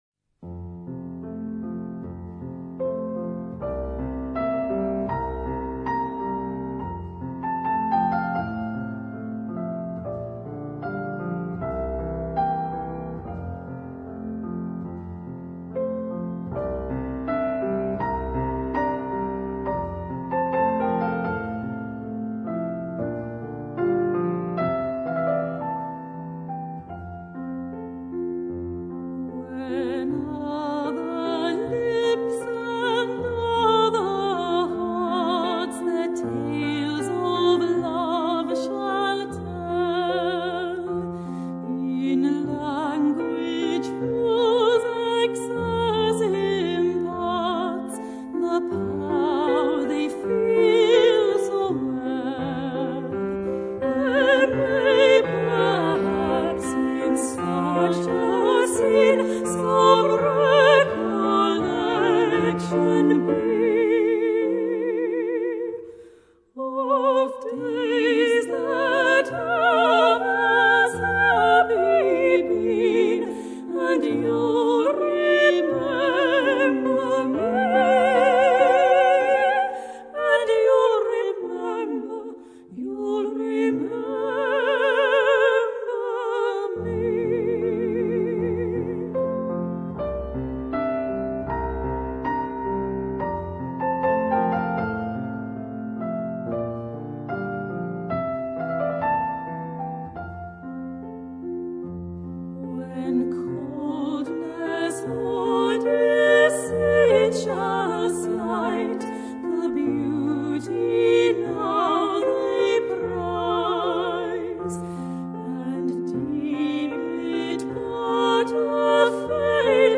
Classicas